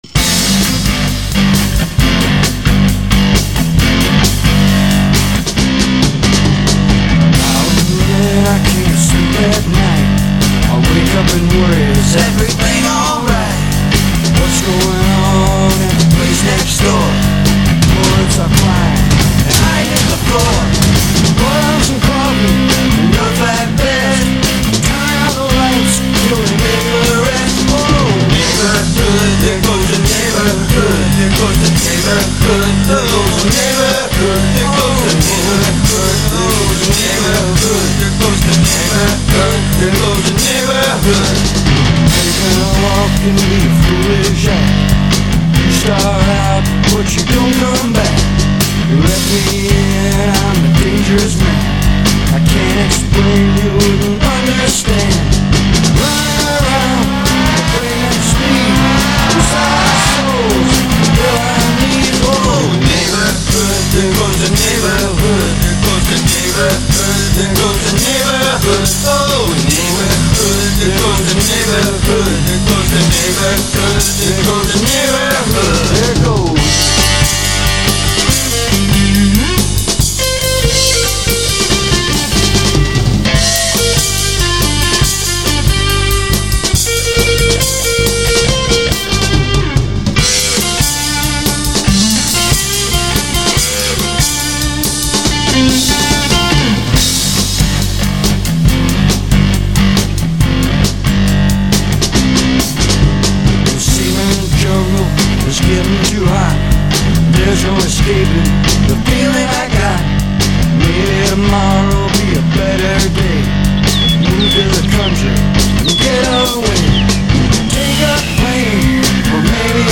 Rock & Roll
Rhythm & Blues